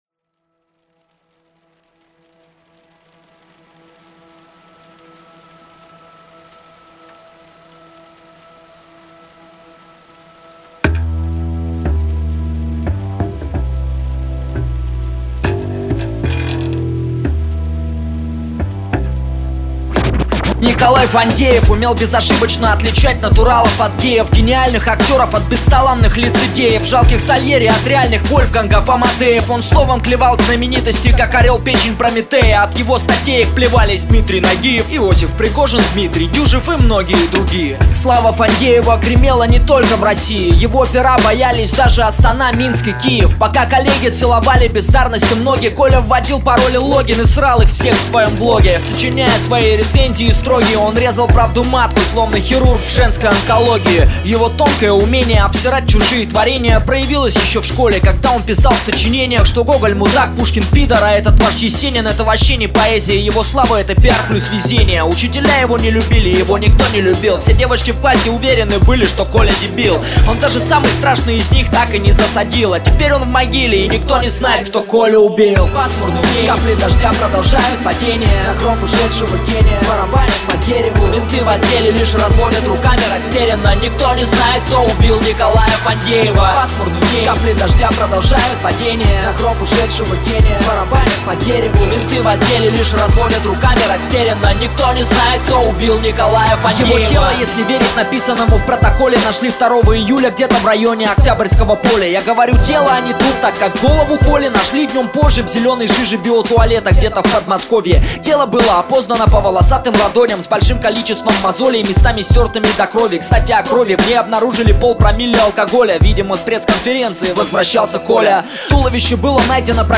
гитара и вокал
клавиши
бас-гитара
барабаны
И вот так, за один день, родилась эта ироничная композиция.